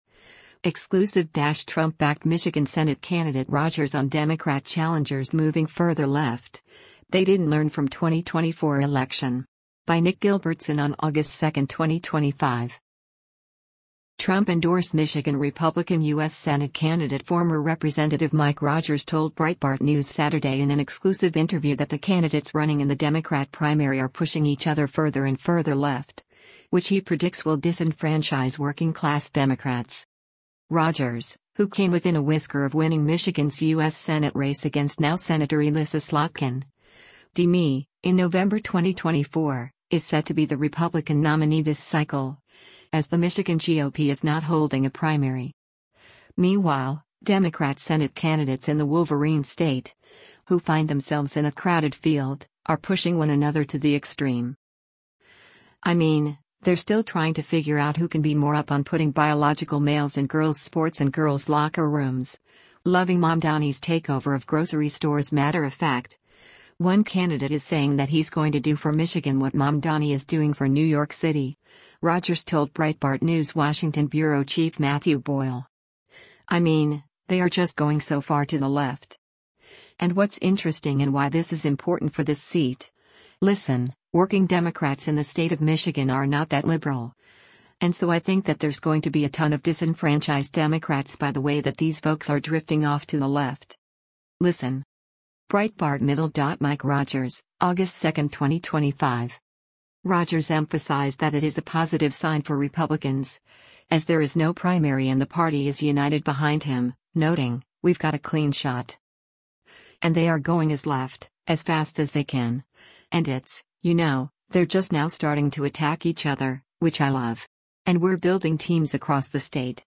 Trump-endorsed Michigan Republican U.S. Senate candidate former Rep. Mike Rogers told Breitbart News Saturday in an exclusive interview that the candidates running in the Democrat primary are pushing each other further and further left, which he predicts will disenfranchise working-class Democrats.
Breitbart News Saturday airs on SiriusXM Patriot 125 from 10:00 a.m. to 1:00 p.m. Eastern.